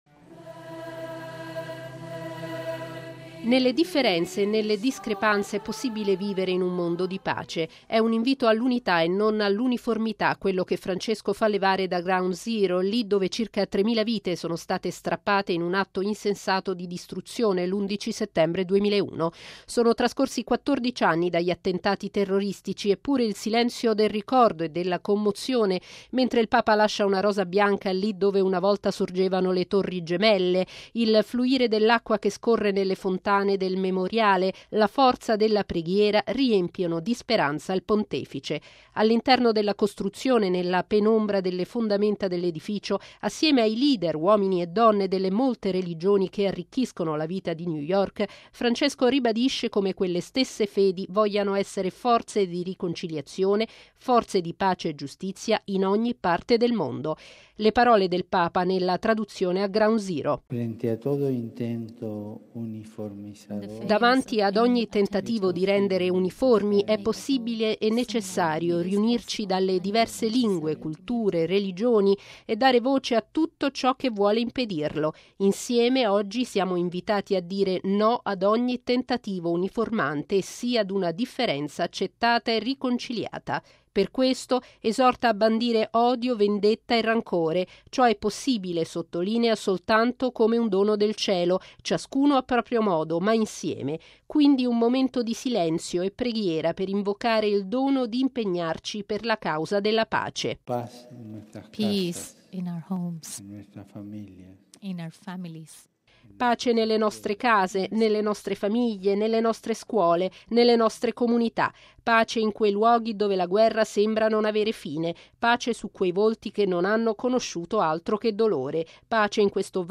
È il senso delle parole di Papa Francesco nell’incontro interreligioso a Ground Zero, a New York, dove 14 anni fa sorgeva il World Trade Center, con le sue Torri Gemelle.